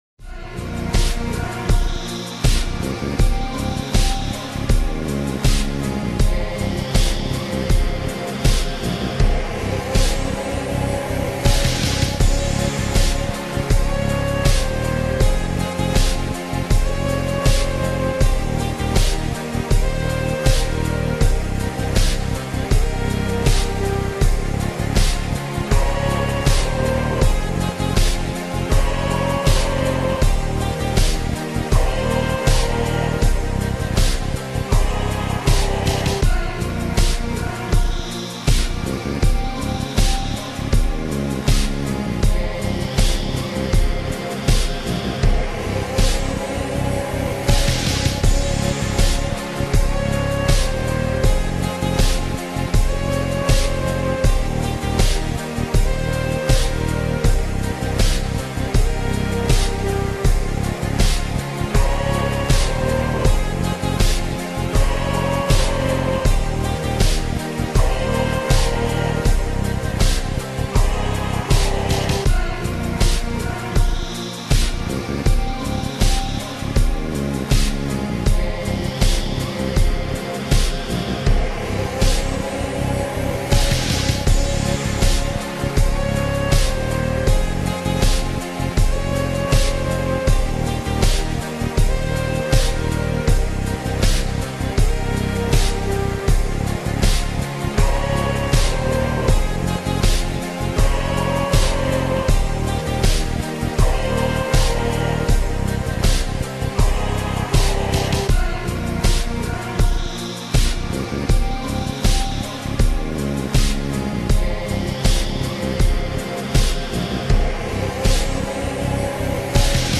موزیک میم بی کلام اینستا